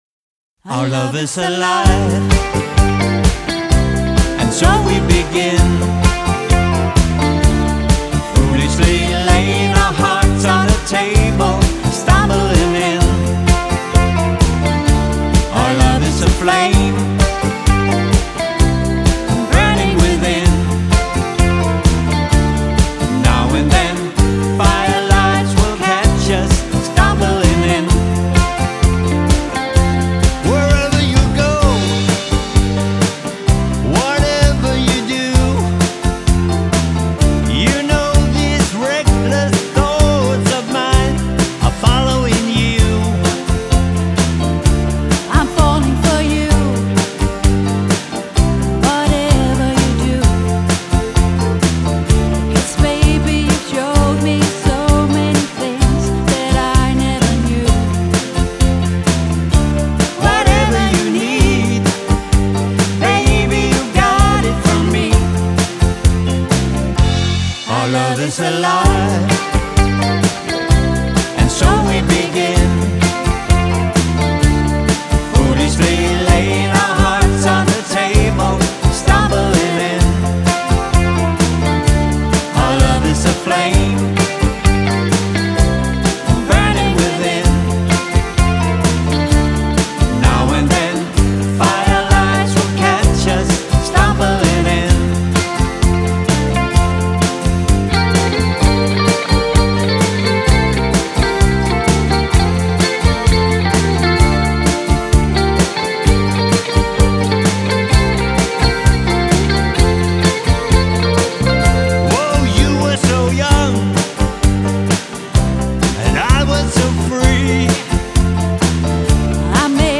guitar og vokal
keyboards og vokal
• Coverband